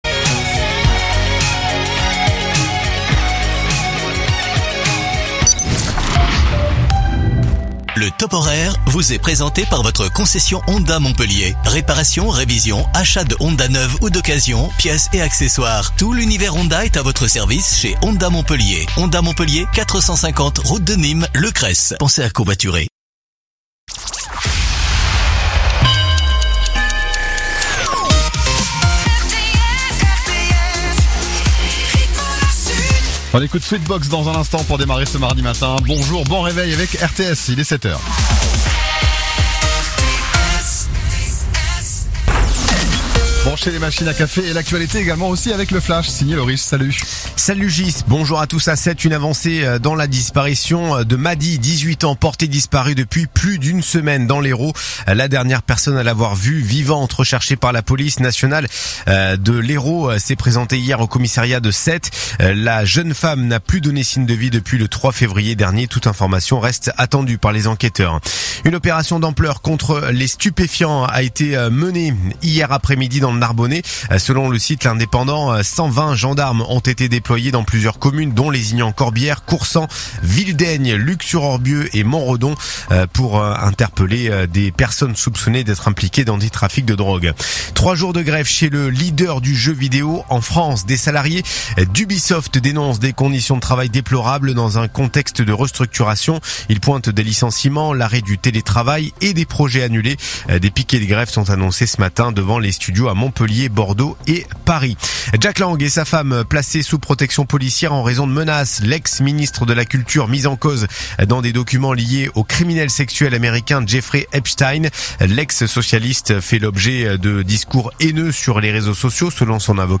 RTS : Réécoutez les flash infos et les différentes chroniques de votre radio⬦